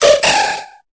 Cri de Manzaï dans Pokémon Épée et Bouclier.